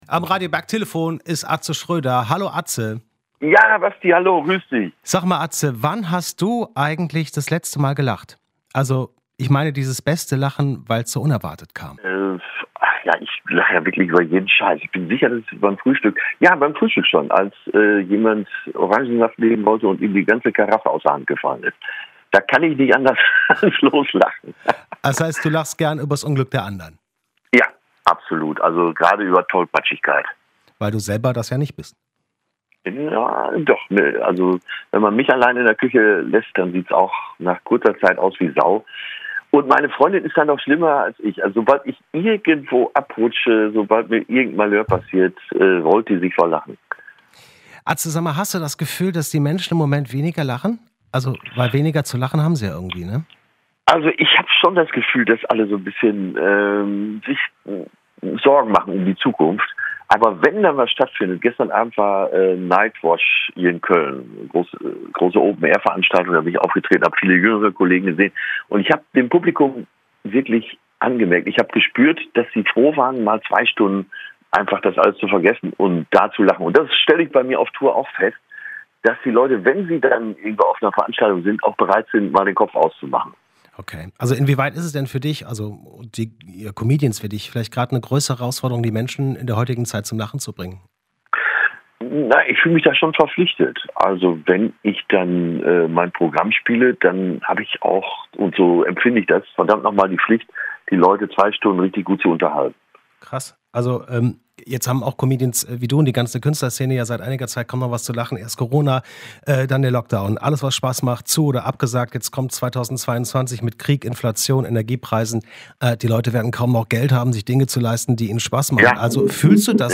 atze_interview.mp3